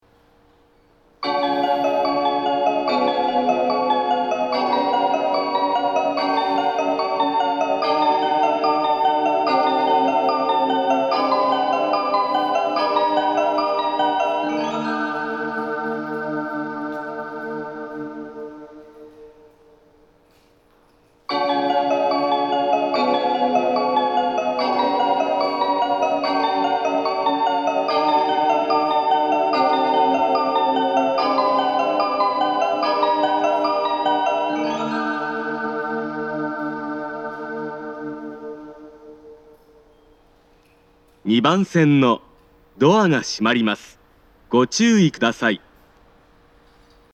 発車メロディーの音量は小さいです。
発車メロディー
2コーラスです!たまにある時間調整する列車が狙い目です。